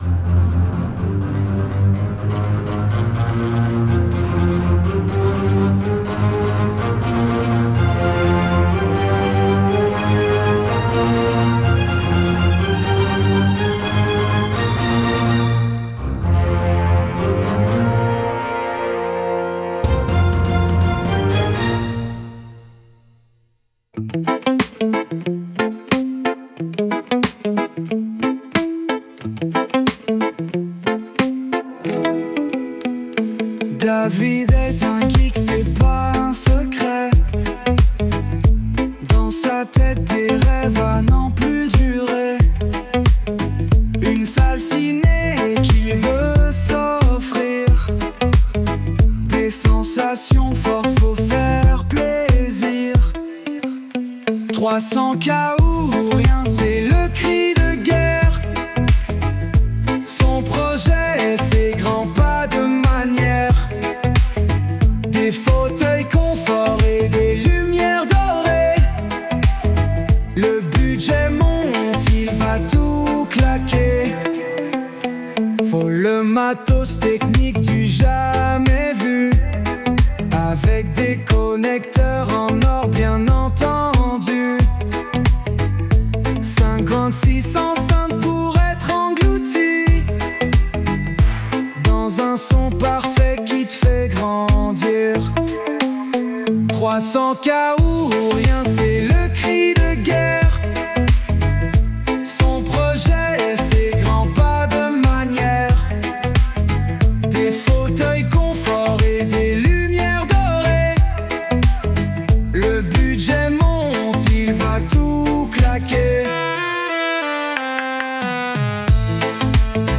Discussions entre potes - La Confrérie est un podcast de discussion entre potes sur les sujets qui nous intéressent (En general cela reste cependant tres geek)